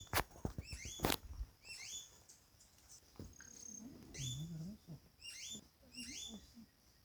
Caneleiro-verde (Pachyramphus viridis)
Nome em Inglês: Green-backed Becard
Localidade ou área protegida: Santa Ana
Condição: Selvagem
Certeza: Observado, Gravado Vocal
Anambe-verdoso--1-_1.mp3